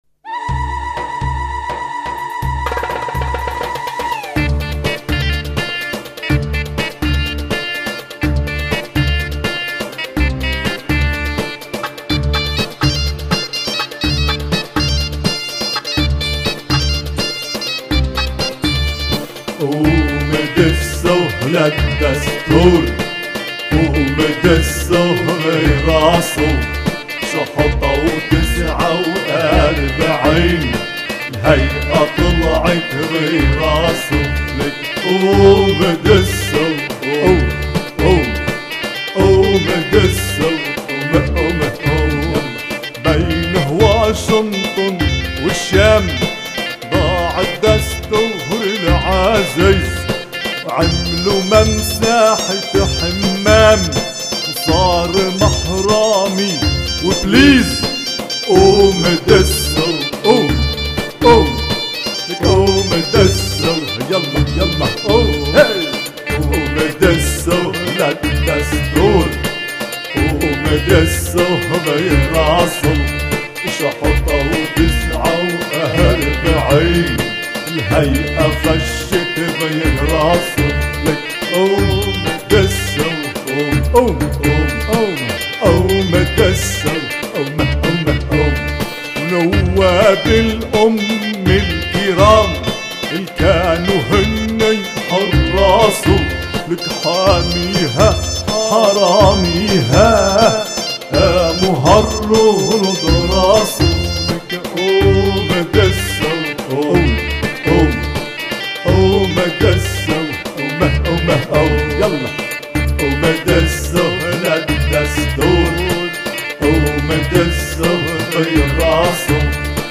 8. سجلت في فرنسا في 11 ايلول